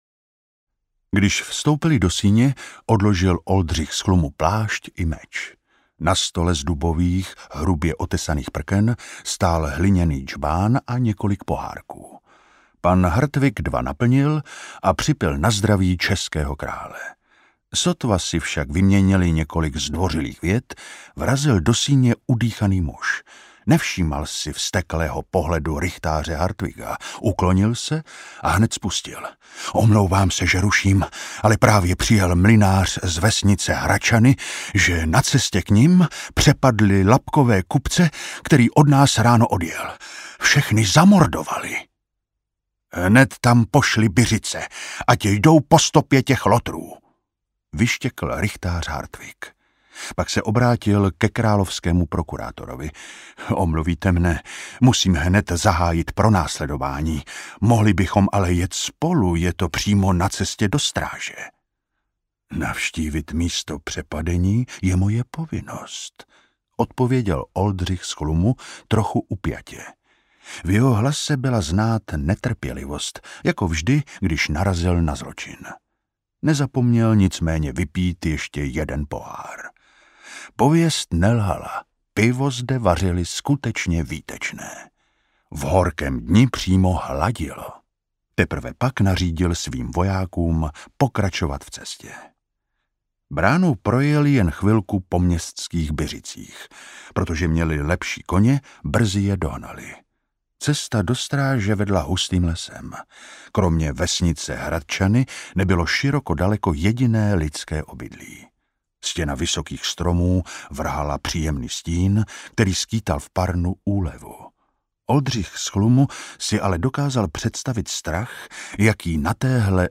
Jménem krále audiokniha
Ukázka z knihy
Audiokniha je bez hudebních předělů a podkresů.
| Vyrobilo studio Soundguru.